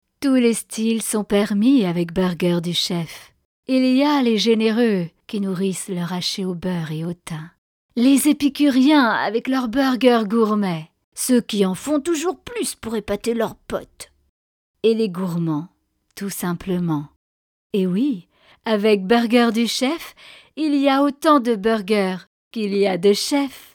Lecture incarnée - Portrait de Femme Leyla Zana